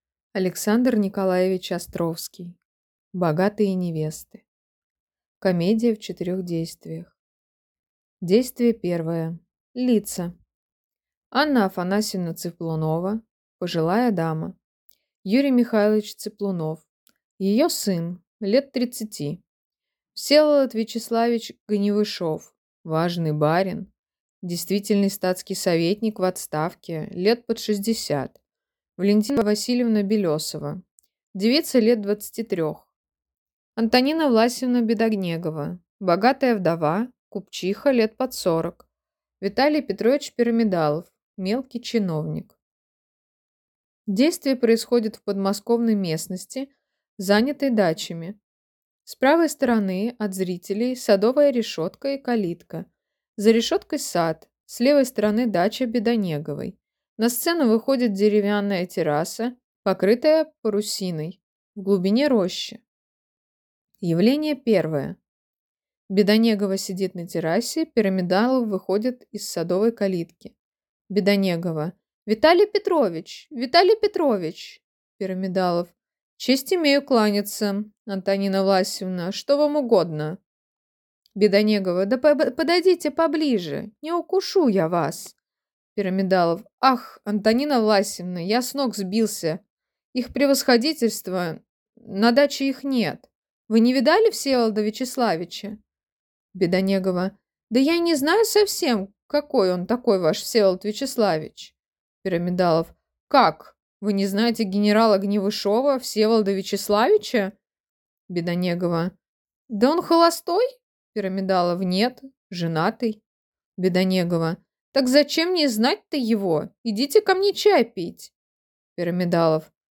Аудиокнига Богатые невесты | Библиотека аудиокниг